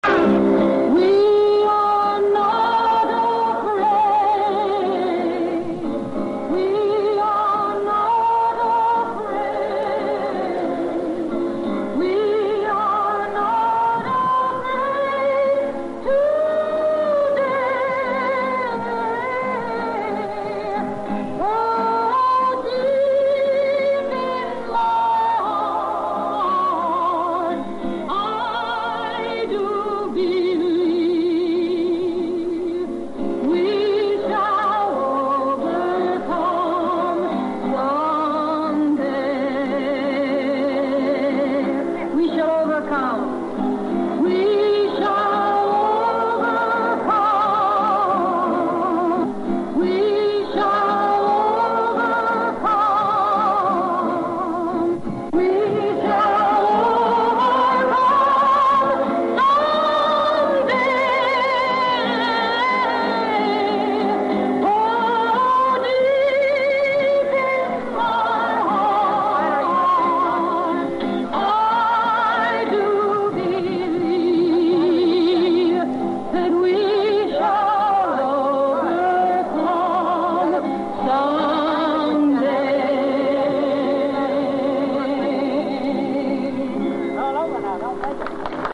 Тысячи, десятки тысяч людей подпевали «Жанне д'Арк» движения за гражданские права, 22-летней Джоан Баэз (Joan Báez), когда она под гитару исполнила «We shall overcome».
Вместе с манифестантами поёт Джоан Баэз: